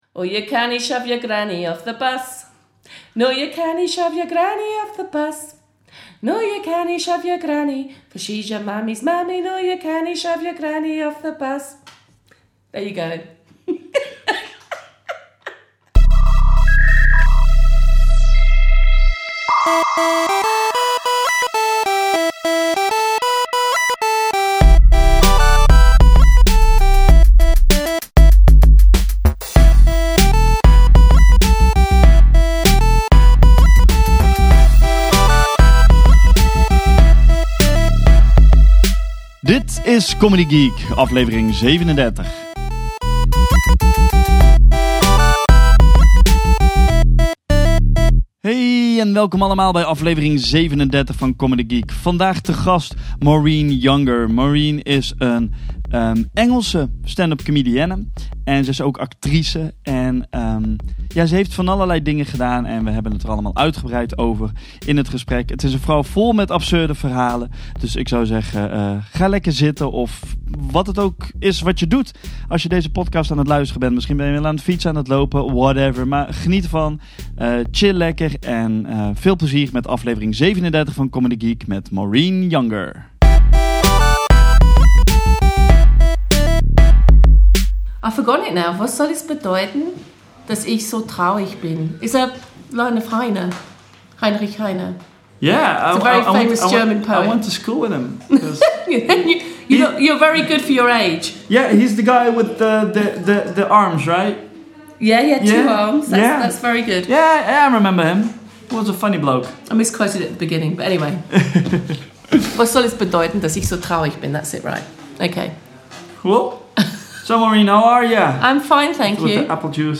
In deze podcast geen interviews, maar openhartige en eerlijke gesprekken aan de keukentafel bij de artiest thuis.